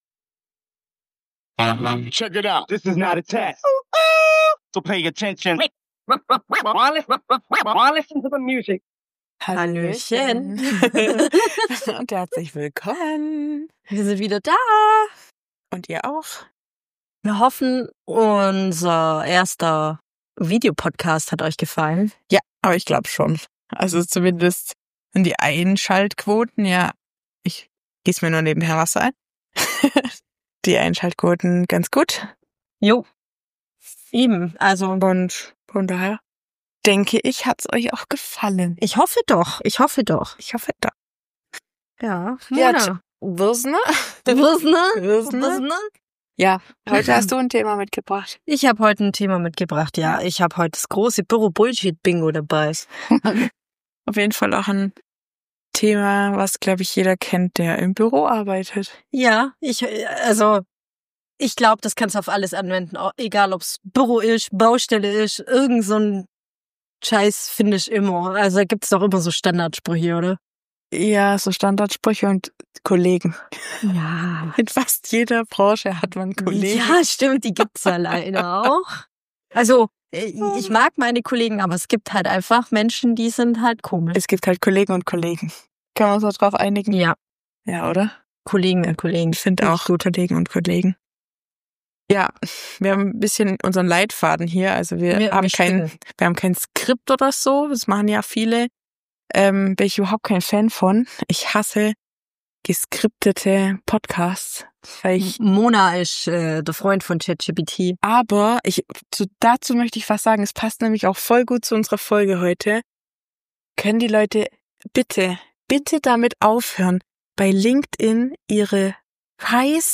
Wir sind zwei beste Freundinnen, die sich seit über 20 Jahren durch das Leben, Liebesdramen, Beauty-Fails und jede Menge WTF-Momente begleiten. Was als Therapiesitzung in Sprachnachrichtenlänge begann, ist jetzt ein Podcast – für alle, die sich im ganz normalen Wahnsinn des Frauseins wiederfinden.